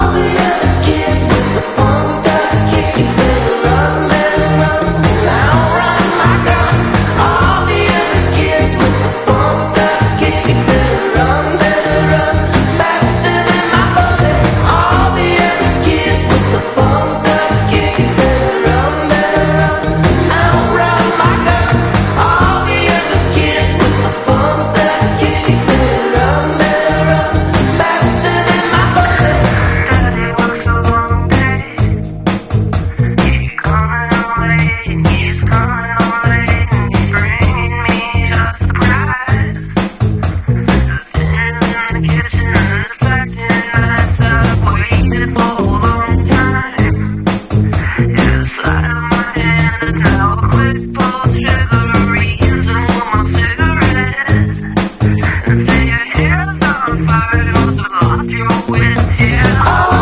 Caught These Insane Southwest Approaches Sound Effects Free Download.
Caught these insane Southwest approaches sound effects free download